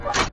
LeverFlip.ogg